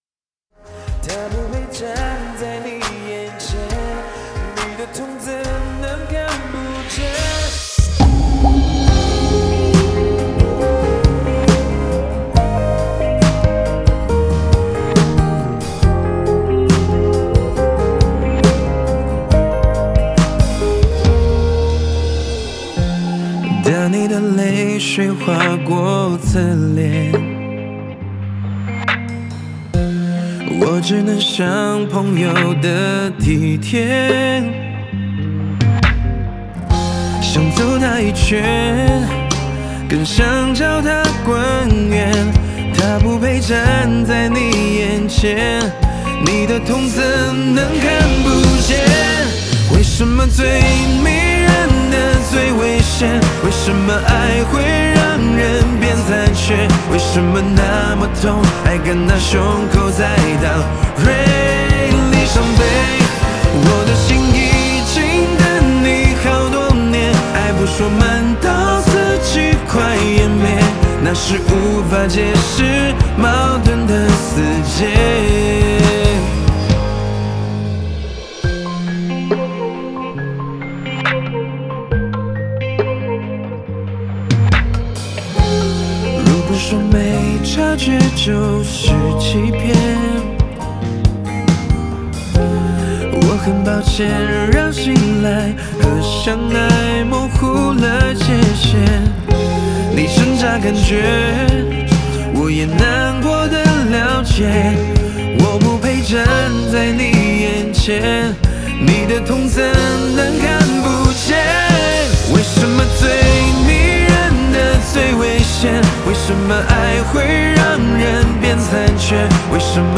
Ps：在线试听为压缩音质节选，体验无损音质请下载完整版 曾经相信你随口说的誓言， 把未来交给你许诺的明天。